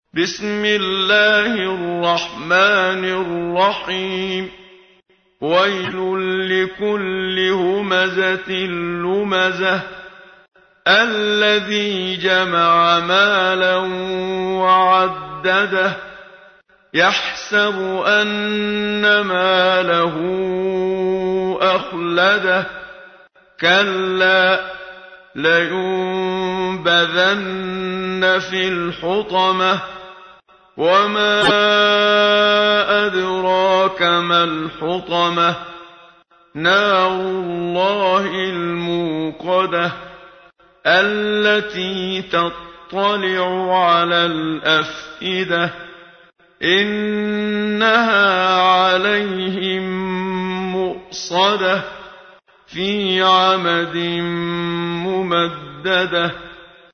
تحميل : 104. سورة الهمزة / القارئ محمد صديق المنشاوي / القرآن الكريم / موقع يا حسين